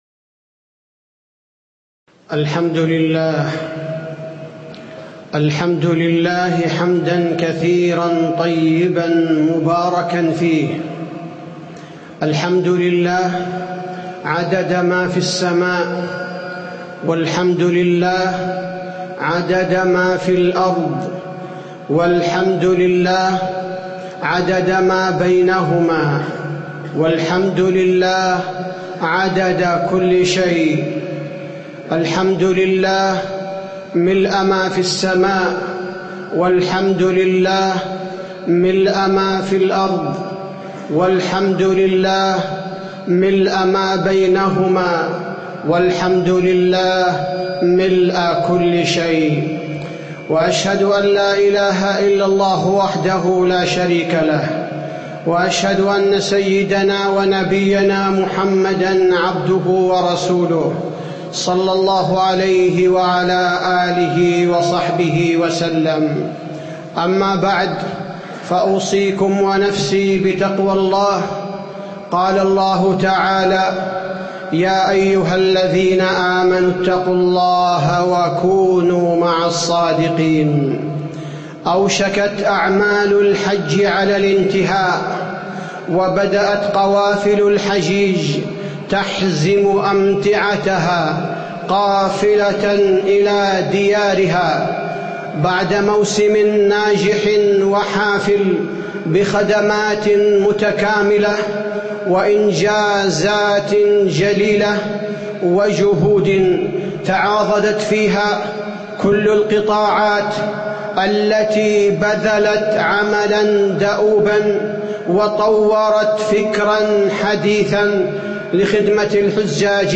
تاريخ النشر ١٥ ذو الحجة ١٤٣٧ هـ المكان: المسجد النبوي الشيخ: فضيلة الشيخ عبدالباري الثبيتي فضيلة الشيخ عبدالباري الثبيتي موسم الحج وتكفير الذنوب